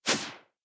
shake.mp3